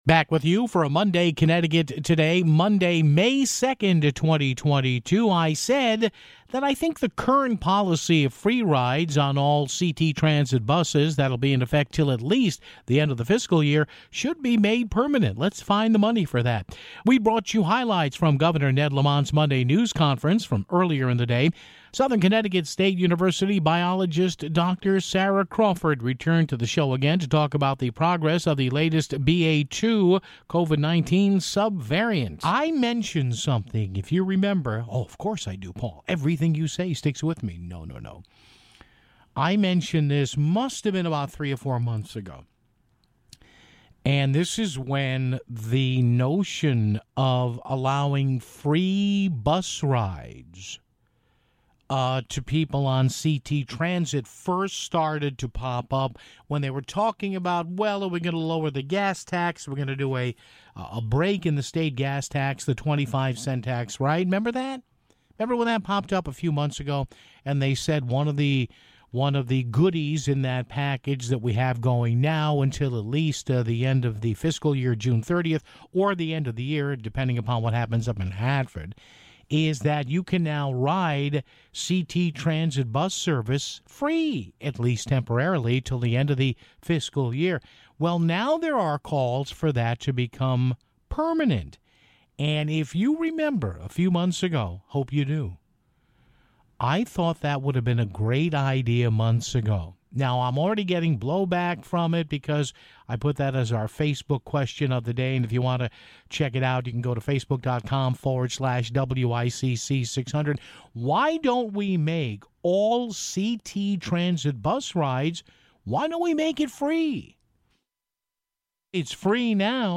We brought you highlights from Governor Ned Lamont's Monday news conference (9:16).